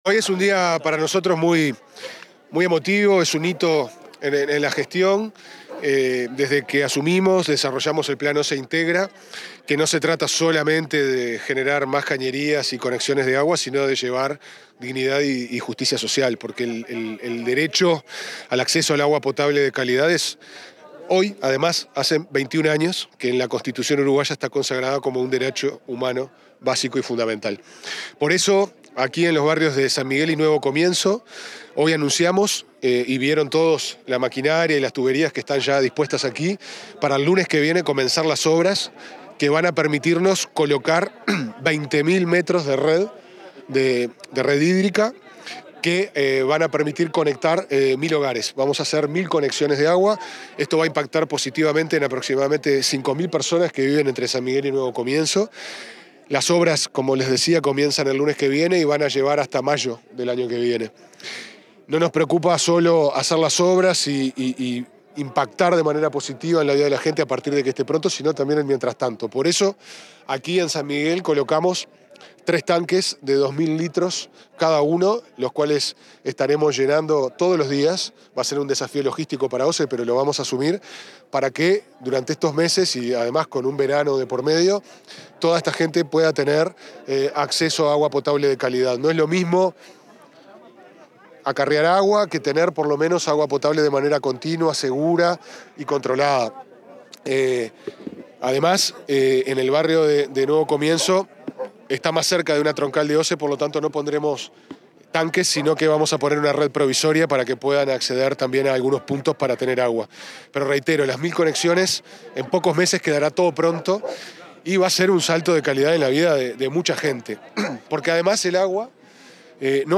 Declaraciones del presidente de OSE, Pablo Ferreri
Declaraciones del presidente de OSE, Pablo Ferreri 31/10/2025 Compartir Facebook X Copiar enlace WhatsApp LinkedIn Tras participar en el lanzamiento de las obras de conexión a red de agua potable en los barrios Nuevo Comienzo y San Miguel de Montevideo, el presidente de OSE, Pablo Ferreri, dialogó con la prensa.